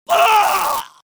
Screams Male 04
Screams Male 04.wav